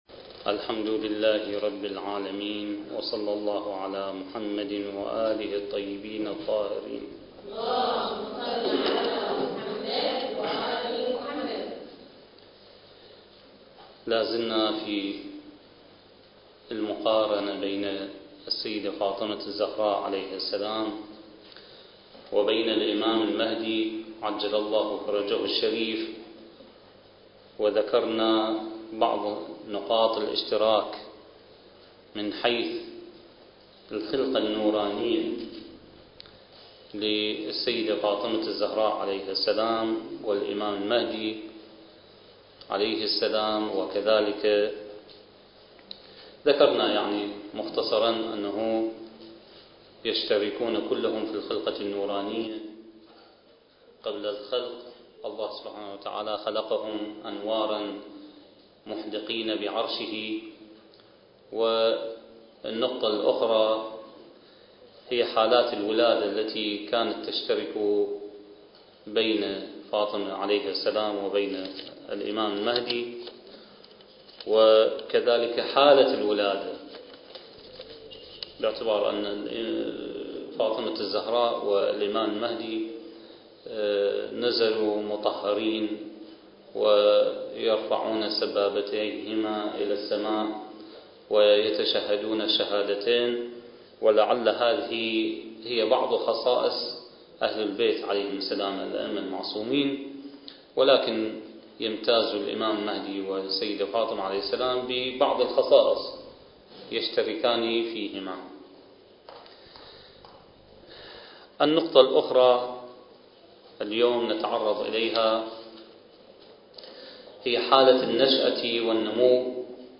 الدورة الثقافية المهدوية للأخوات المؤمنات التي أقامها مركز الدراسات التخصصية في الإمام المهدي (عجّل الله فرجه) تحت شعار (بين صمود الانتظار وبشائر الظهور) التاريخ: 2006